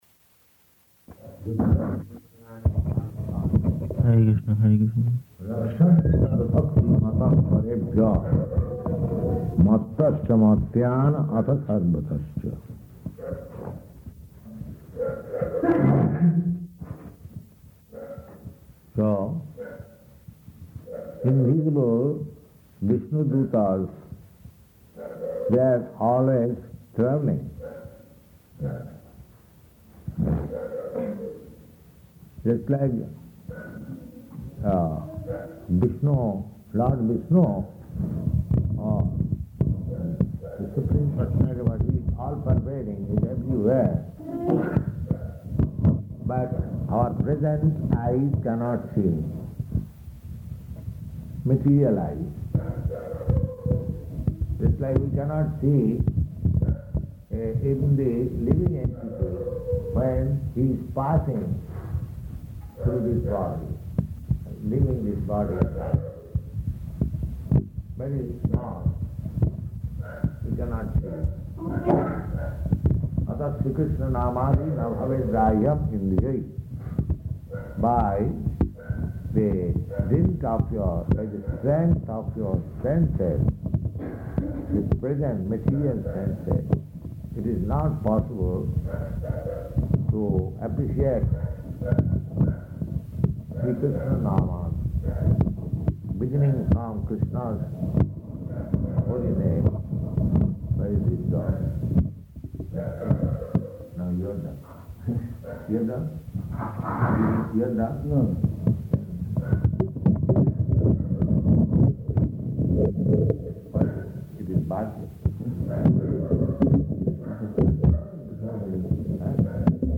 Śrīmad-Bhāgavatam 6.3.18 --:-- --:-- Type: Srimad-Bhagavatam Dated: February 11th 1971 Location: Gorakphur Audio file: 710211SB-GORAKPHUR.mp3 Devotee: [introducing recording] Hare Kṛṣṇa.